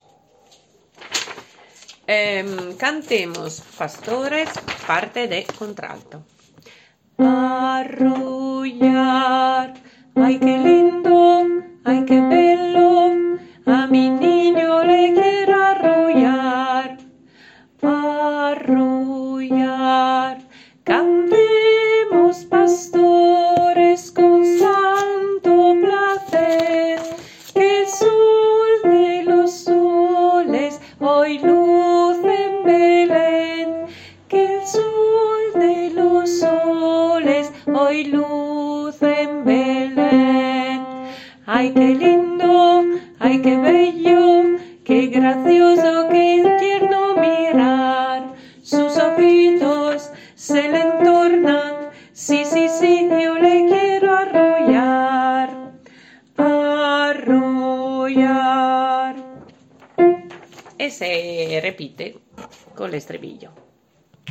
CONTRALTO